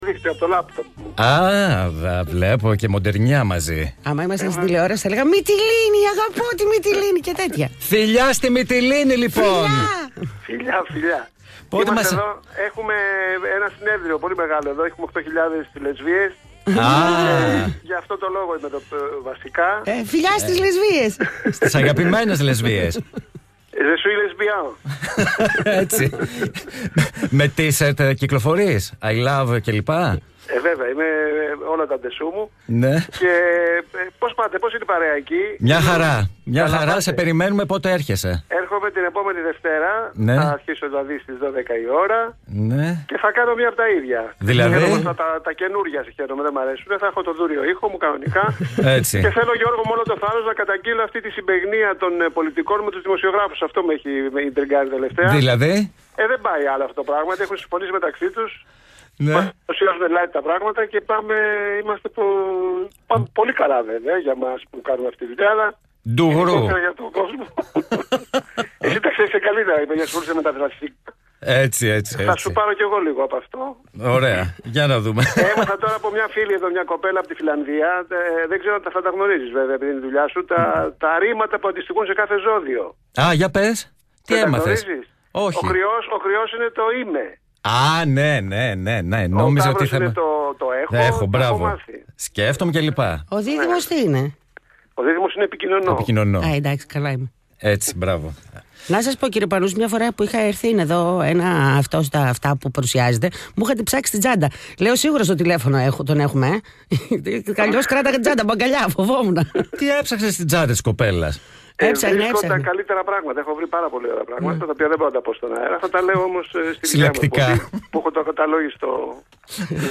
Tzimis-Panousis-Tilefwniki-Synomilia-CITY995.mp3